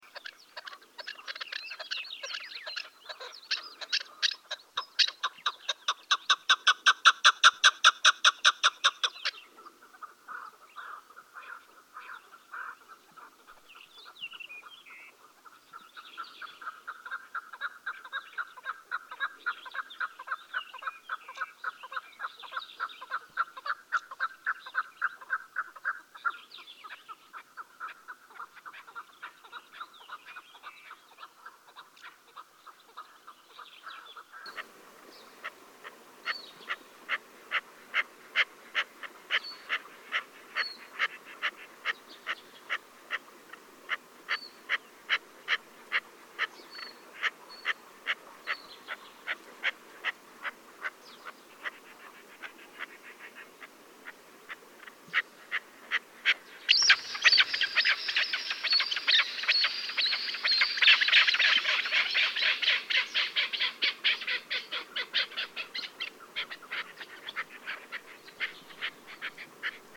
Звуки кеклика
3. Как звучит голос кеклика